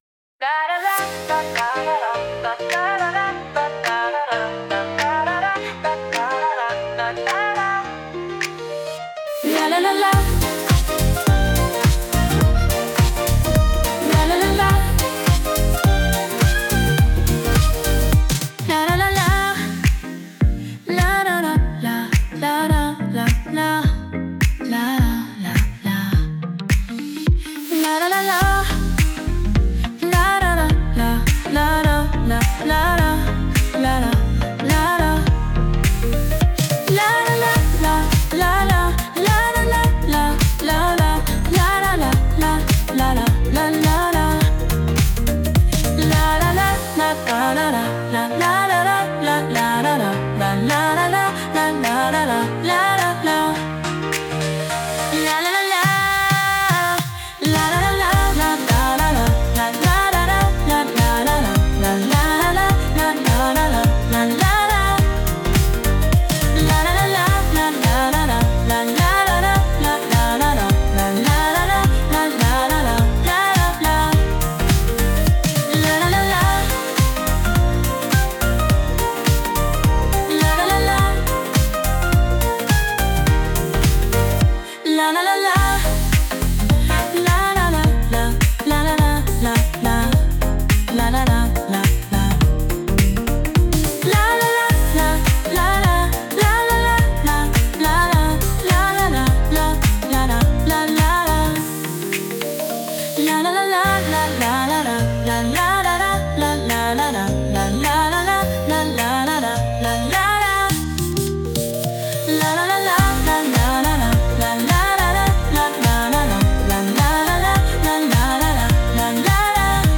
With Vocals / 歌あり
タイトル通り、明るい女性の声で「ラララ〜♪」と歌う、元気いっぱいのポップ・チューン！
複雑なリズムは一切なし。